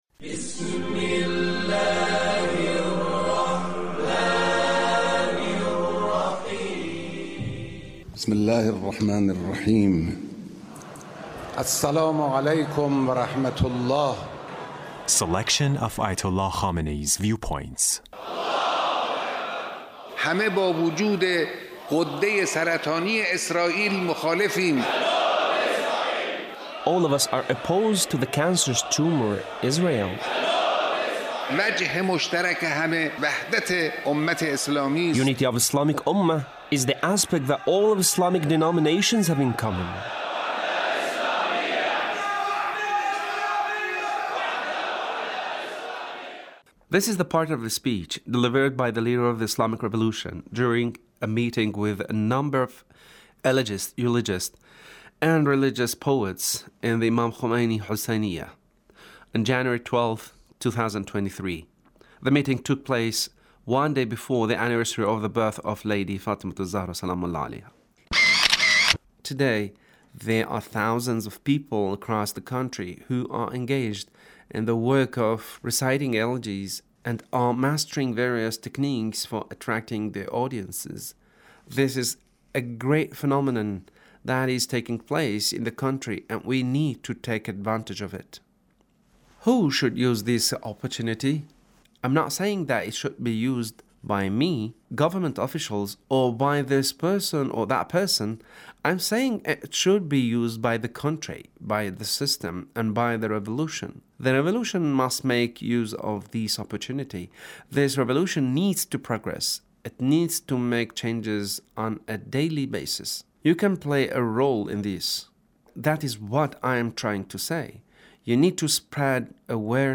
Leader's Speech (1632)
Leader's Speech meeting with Eulogists